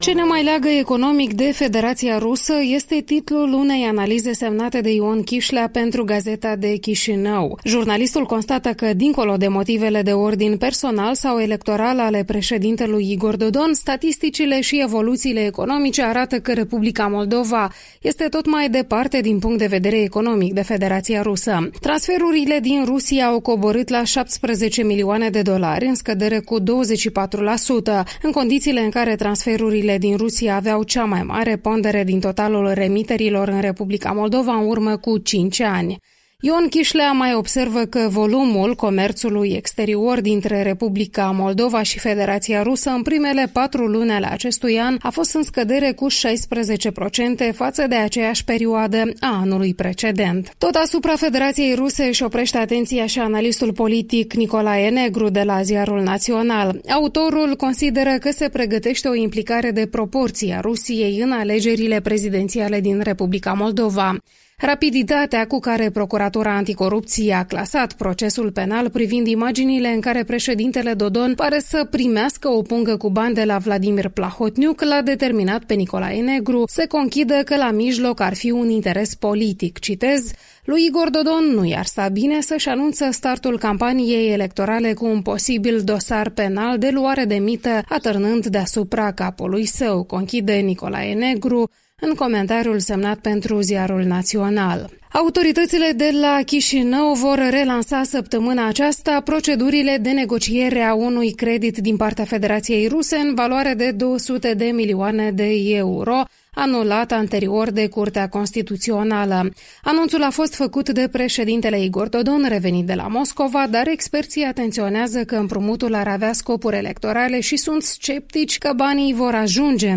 Revista presei matinale la radio Europa Liberă.